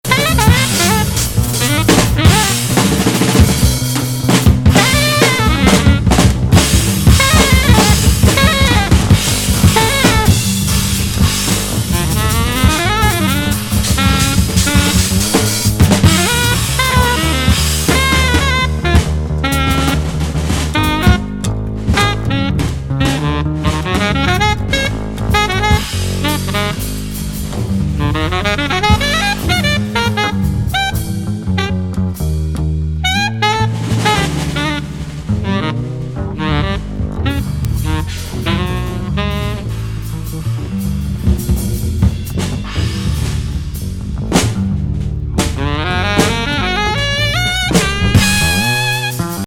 縦横無尽なサックスに、忍び寄るようなベース・ライン。切り込むパーカッション